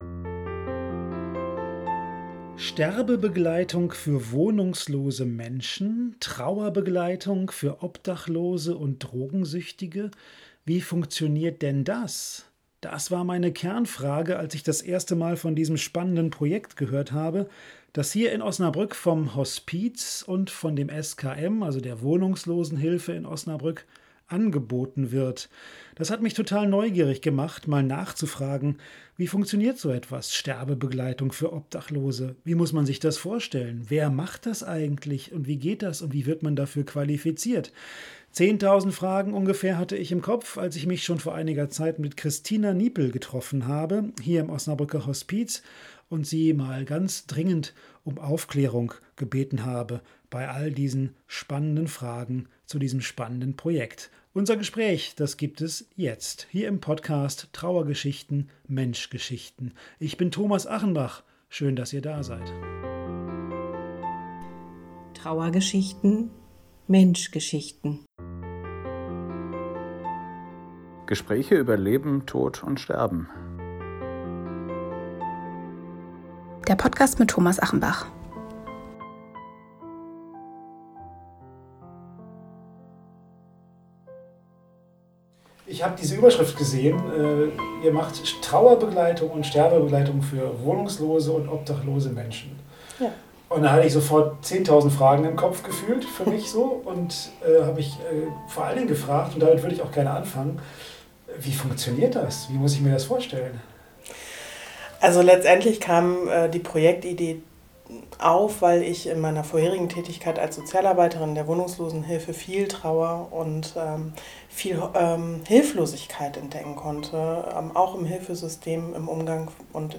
Und was müssen ehrenamtliche Helfer alles beachten? Ein Gespräch